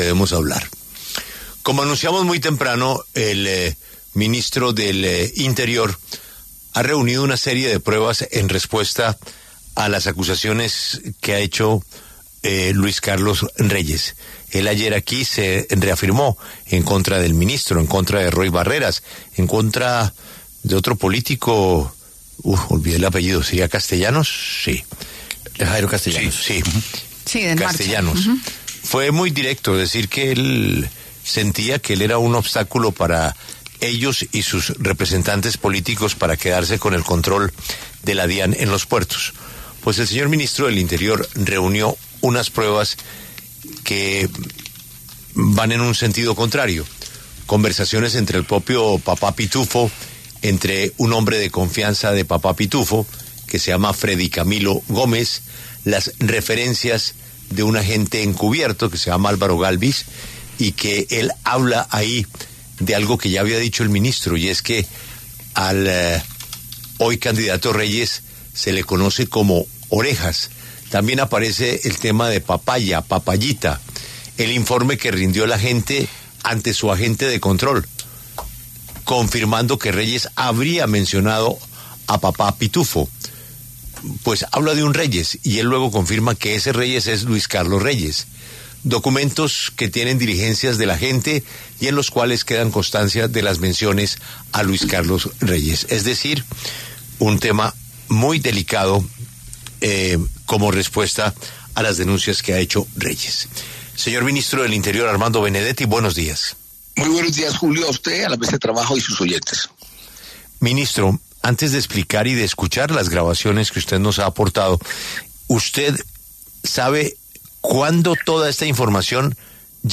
Armando Benedetti pasó por los micrófonos de La W para revelar en primicia las supuestas pruebas que darían cuenta de un favorecimiento de Luis Carlos Reyes a una red de contrabando antioqueña.